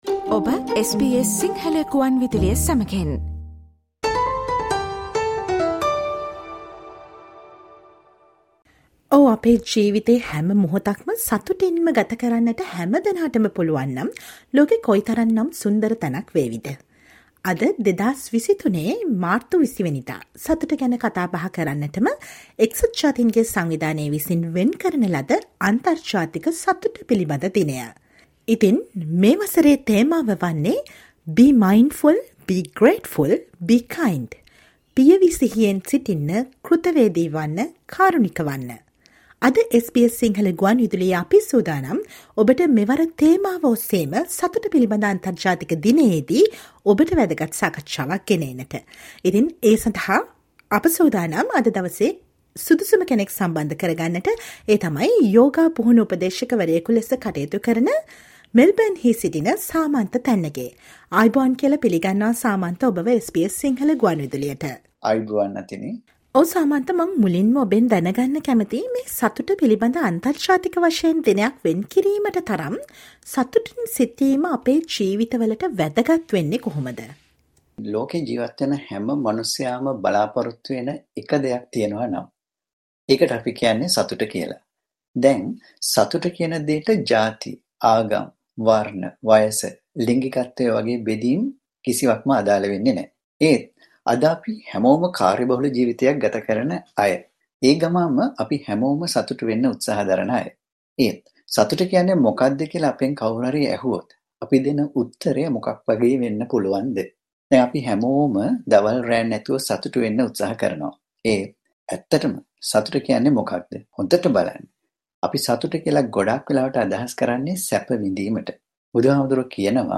SBS Sinhala radio interview to mark The international day for happiness 2023 themed Be mindful, be grateful, be kind.